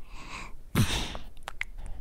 byawn2.ogg